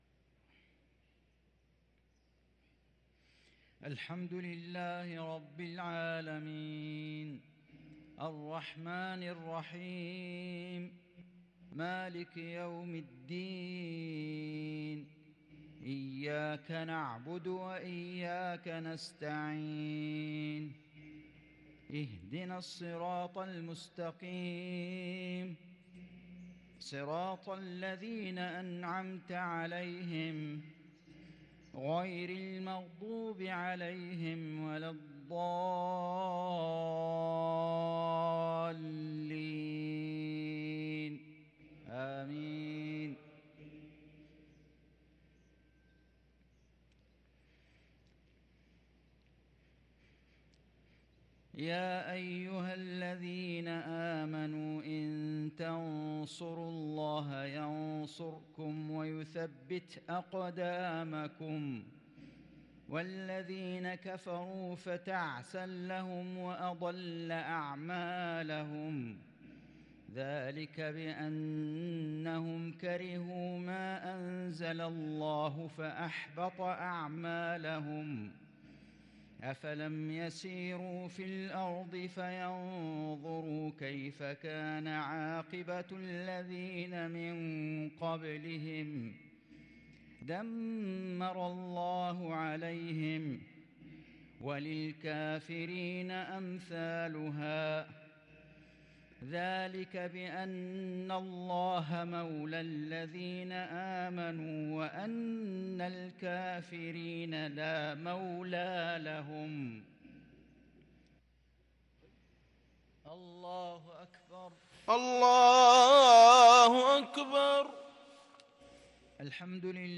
صلاة المغرب للقارئ فيصل غزاوي 1 جمادي الآخر 1444 هـ
تِلَاوَات الْحَرَمَيْن .